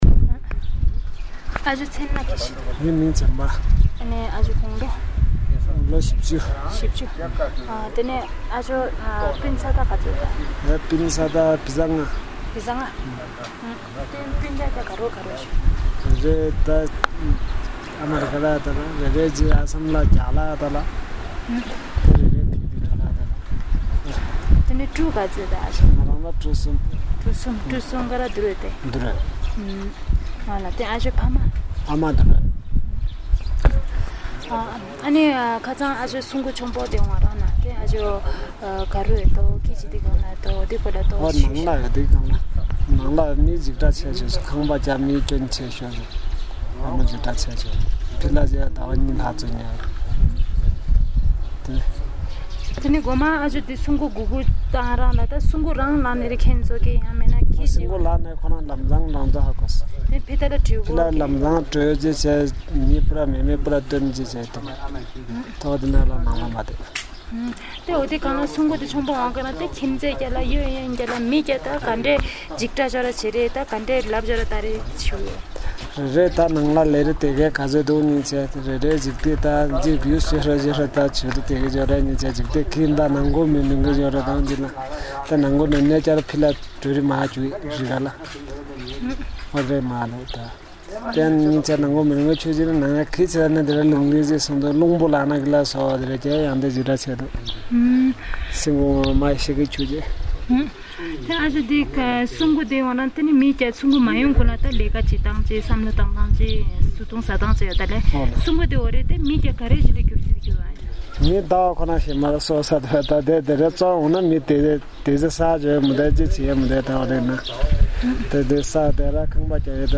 Interview of a community member on the 2015 Nepal Earthquakes
Audio Interview